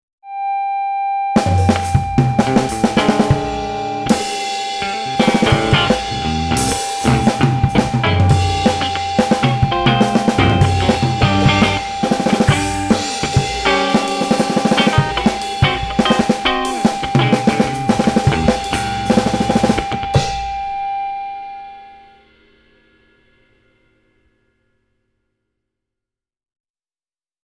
enregistré à Herblay